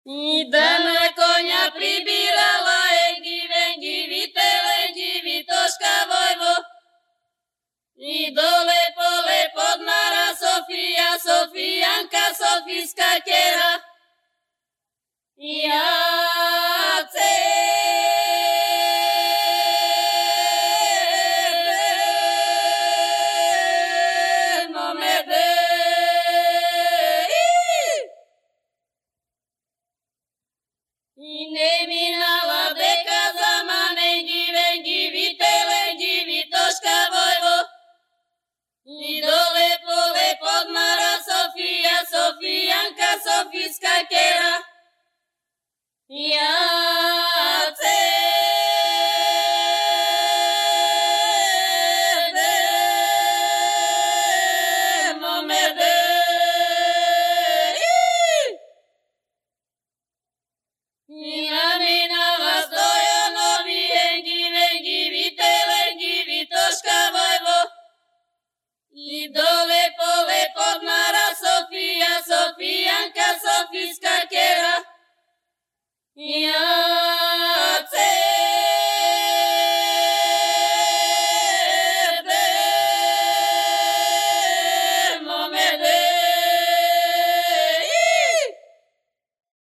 Three-part Bulgarian vocal piece. Fieldwork recording
Three-partShopSinging.mp3